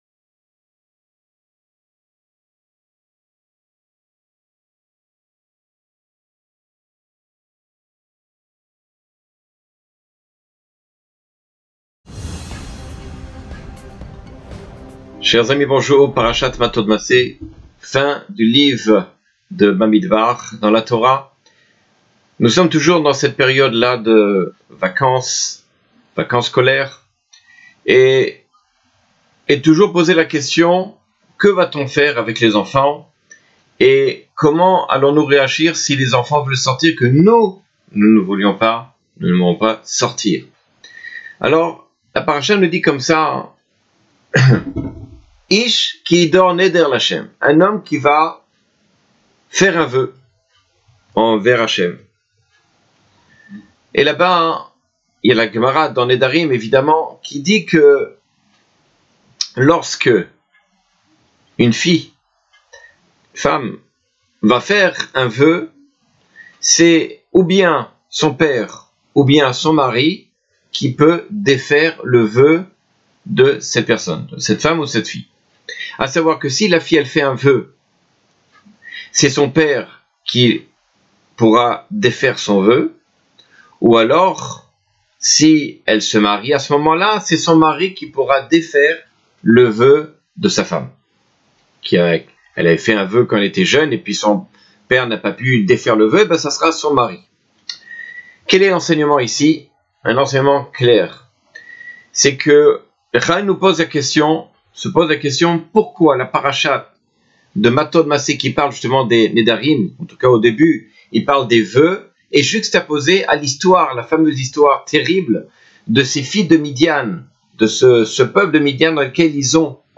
Cours sur l'éducation, Mattot-Massei 5779 : Ma fille en vacances - Beth Haketiva